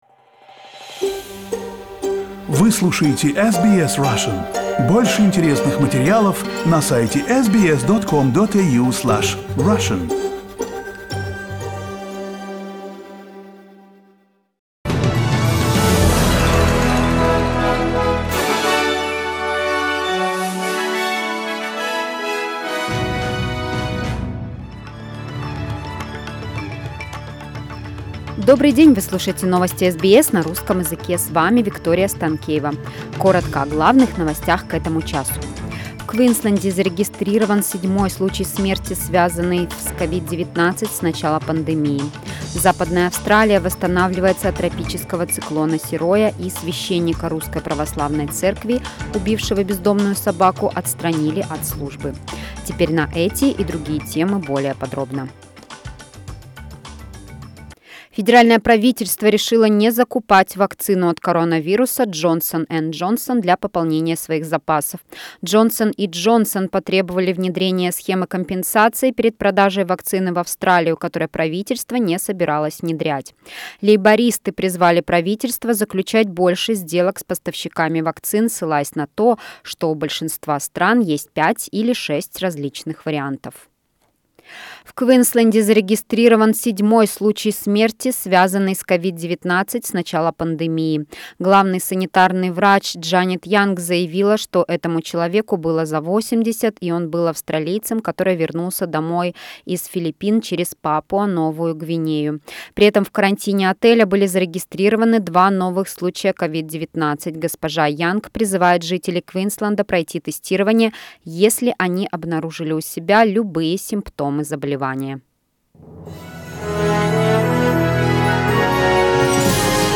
News bulletin April 13th